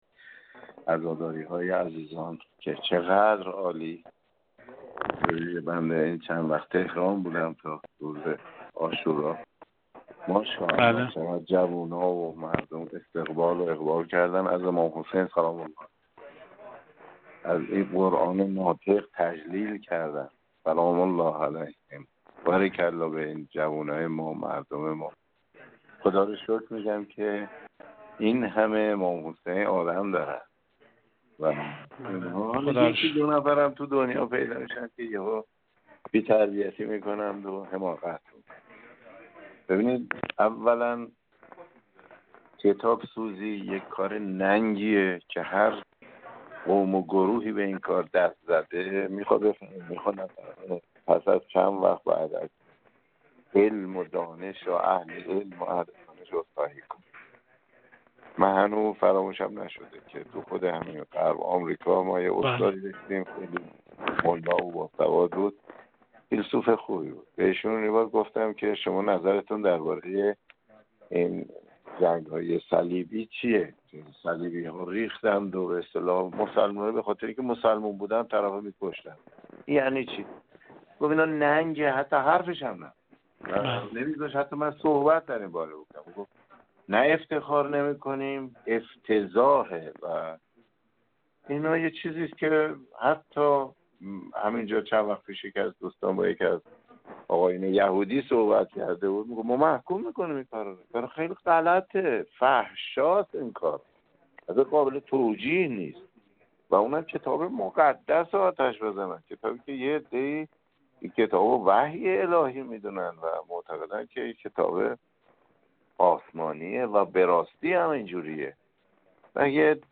حجت‌الاسلام و المسلمین مرتضی آقاتهرانی، رئیس کمیسیون فرهنگی مجلس شورای اسلامی، در گفت‌وگو با ایکنا درباره تکرار قرآن‌سوزی‌ در سوئد و دانمارک با مجوز و حمایت قانونی دولت گفت: ابتدا باید از حضور پررنگ مردم و به ویژه جوانان در مراسم عزاداری امام حسین(ع) تقدیر کرد.